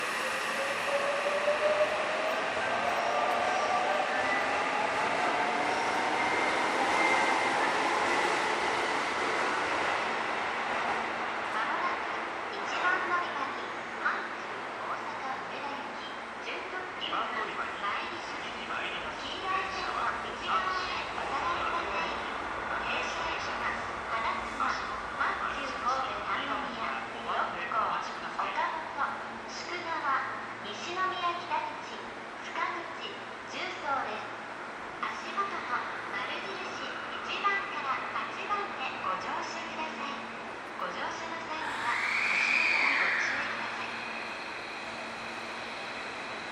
この駅では接近放送が設置されています。
接近放送準特急　大阪梅田行き接近放送です。